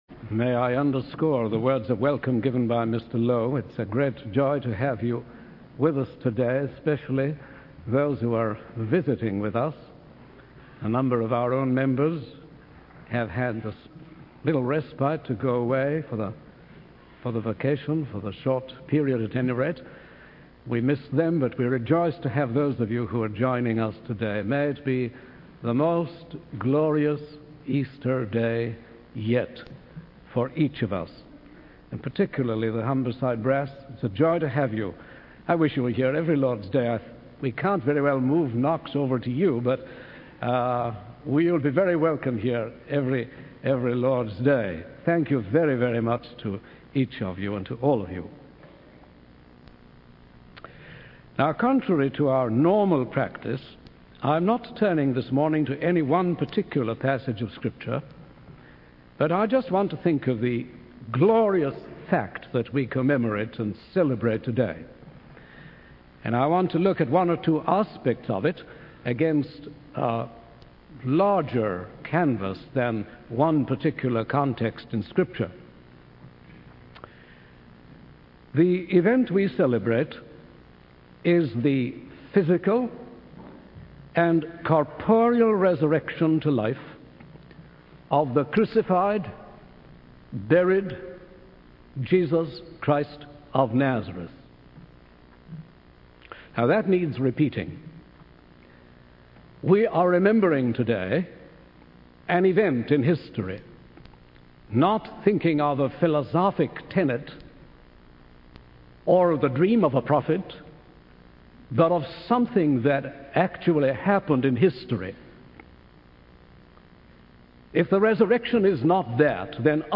In this sermon, the preacher emphasizes the importance of having a personal relationship with Jesus Christ, who is alive and can be communicated with. The preacher highlights that this connection with a living Savior validates the past and brings certainty to the future.